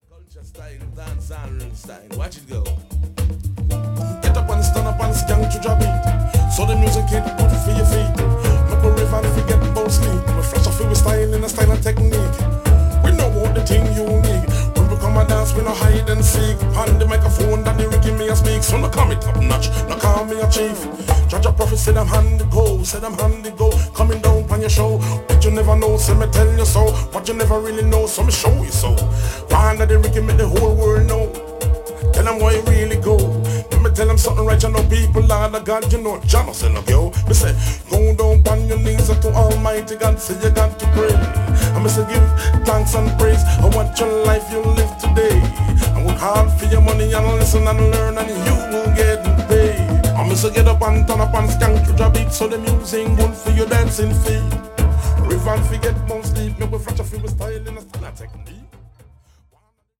ホーム ｜ JAMAICAN MUSIC > DUB
ダブ・プロジェクト